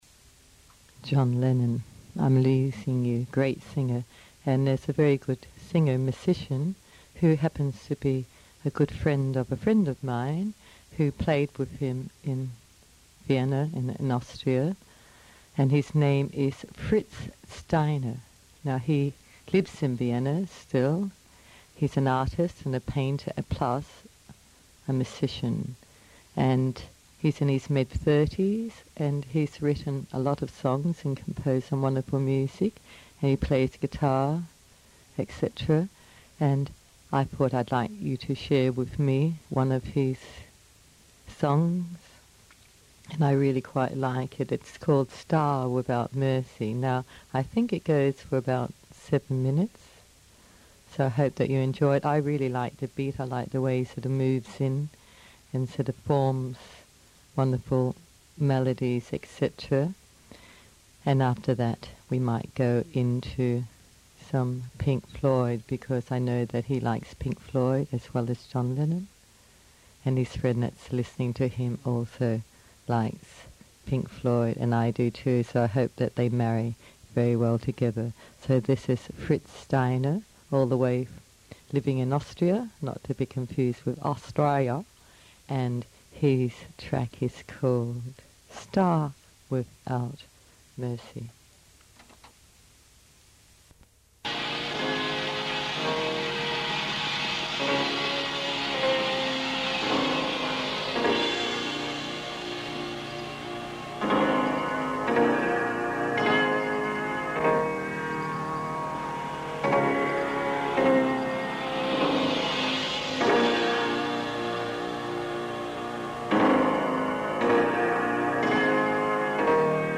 Special Radio Show from Australia - 1992 / Skip the Soapies